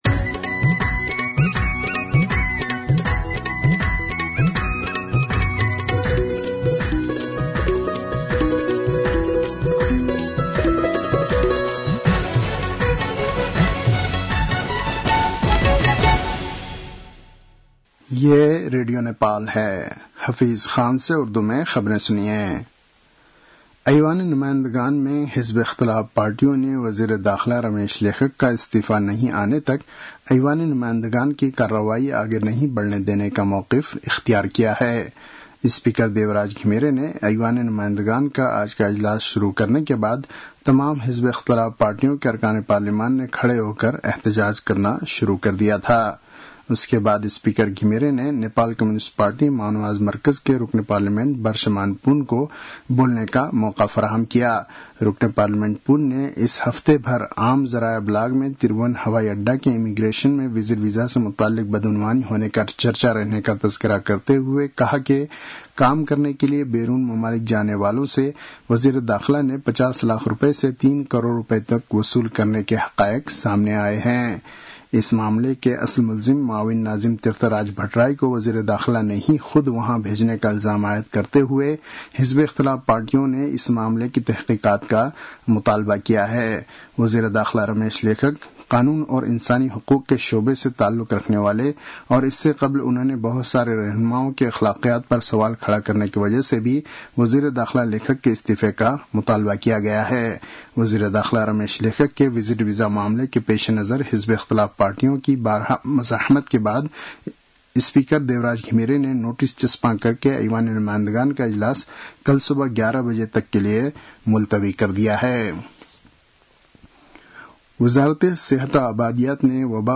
उर्दु भाषामा समाचार : १३ जेठ , २०८२